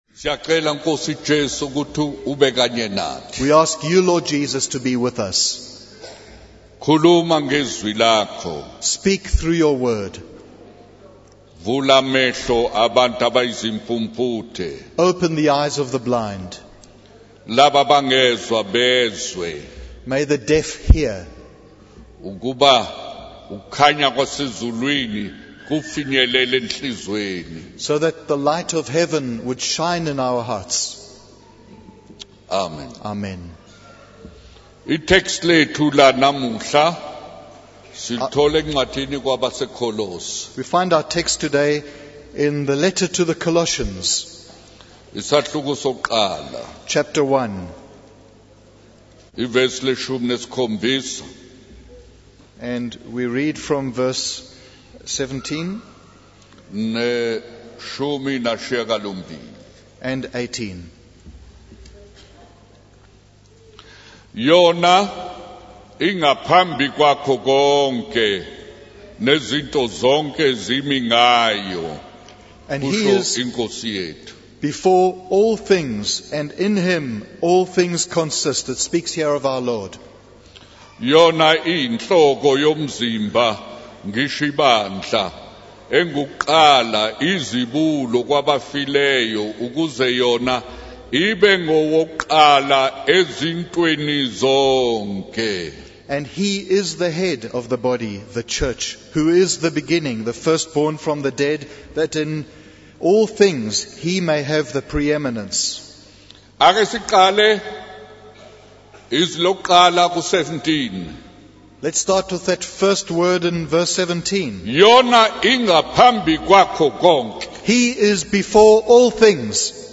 The video is a sermon transcript discussing the impact of Jesus' birth and death on the world.